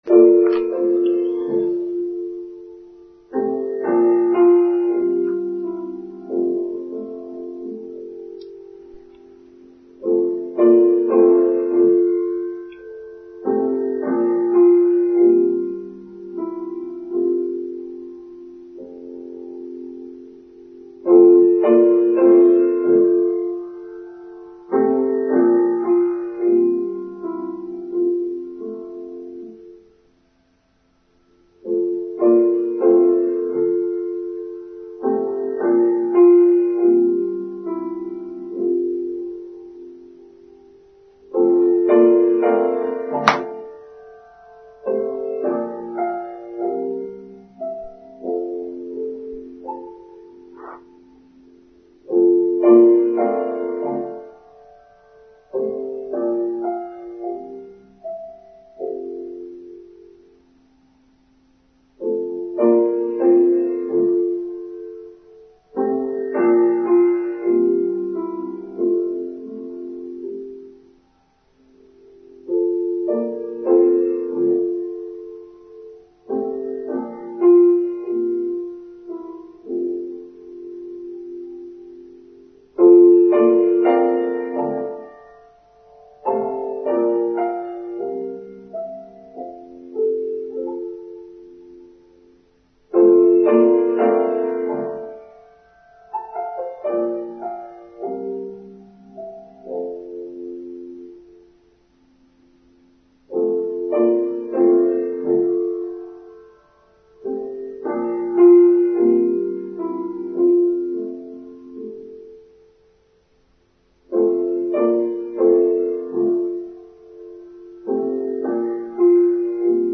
Autumn: Online Service for Sunday 30th October 2022